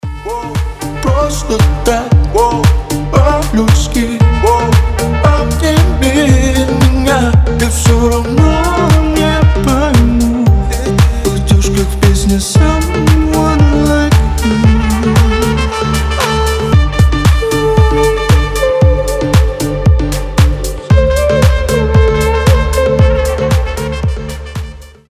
• Качество: 320, Stereo
мужской голос
грустные
house